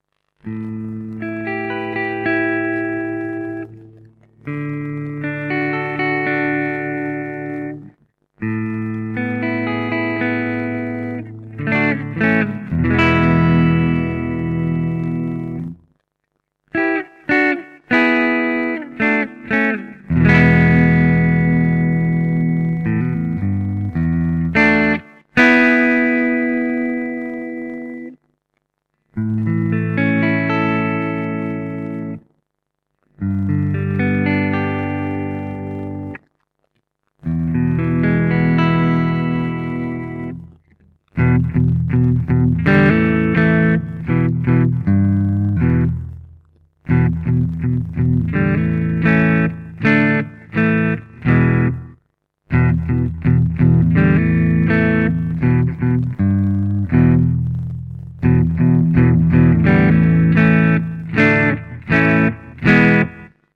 The Behemoth is extremely loud but with a distinctly vintage voice.
It is strong in the upper mid/lower treble area and has a huge bass.